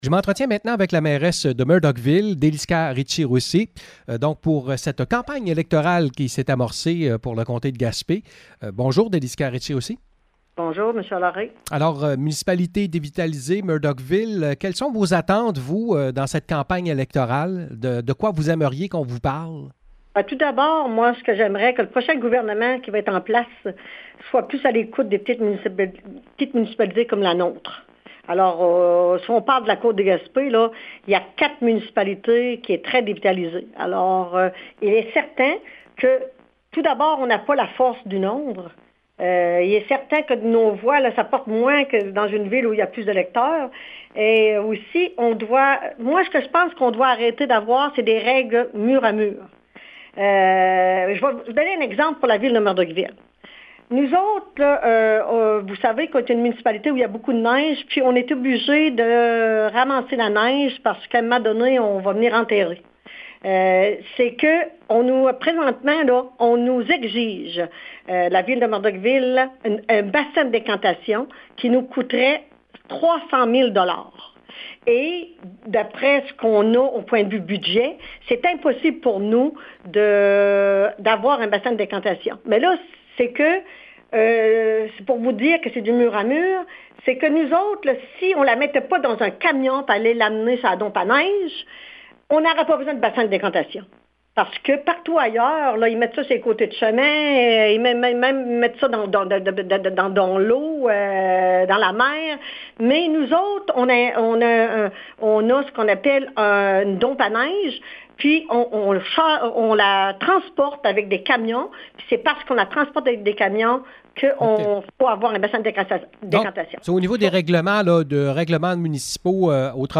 Entrevue avec la mairesse de Murdochville, Délisca Ritchie-Roussy: